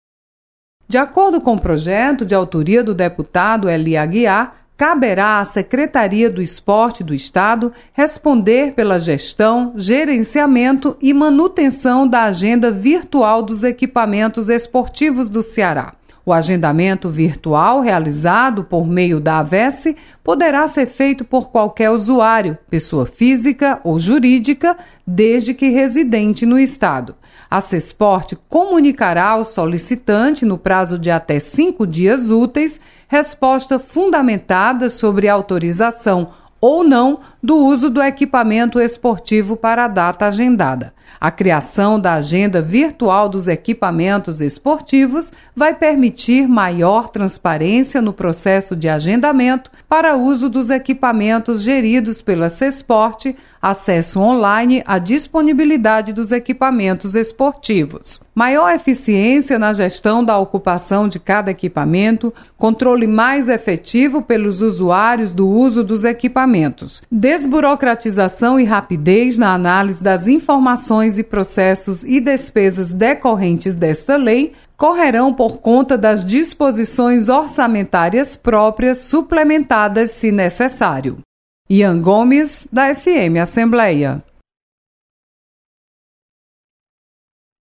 Projeto cria agenda virtual dos equipamentos esportivos. Repórter